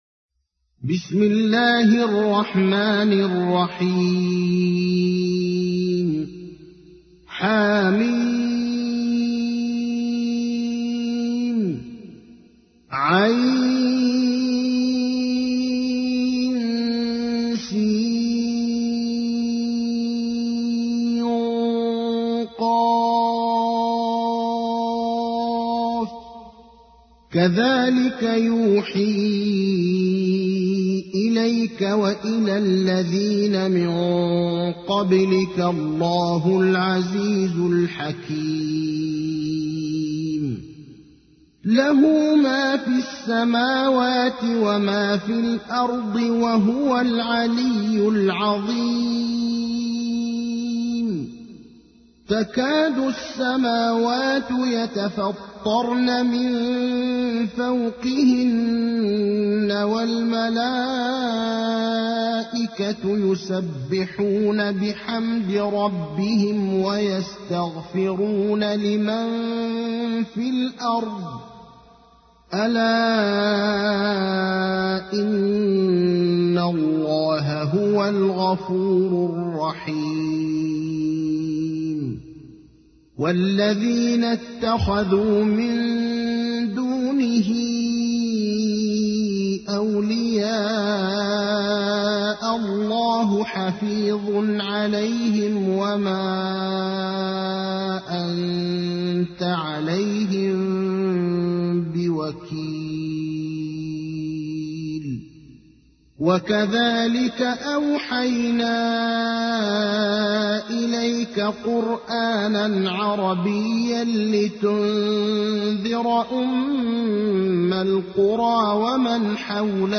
تحميل : 42. سورة الشورى / القارئ ابراهيم الأخضر / القرآن الكريم / موقع يا حسين